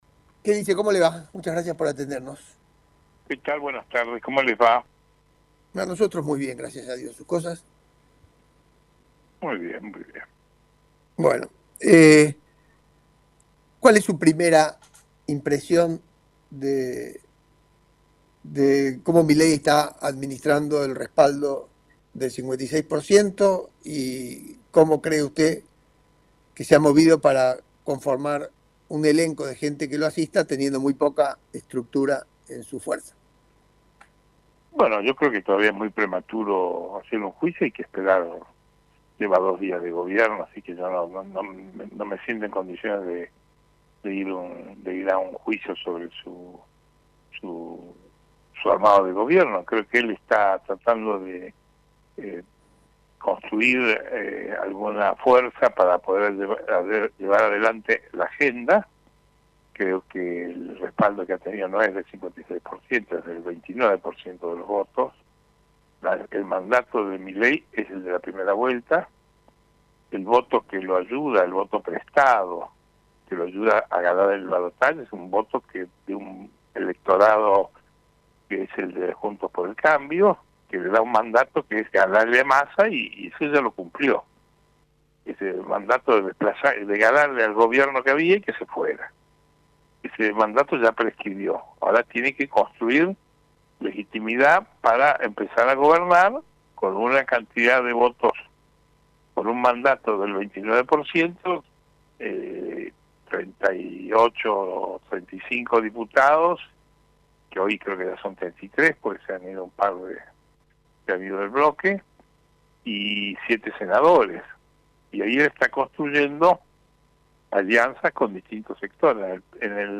EL CONGRESO SERÁ LA PRUEBA DE CAPACIDAD PARA MILEI (Un diálogo por radio)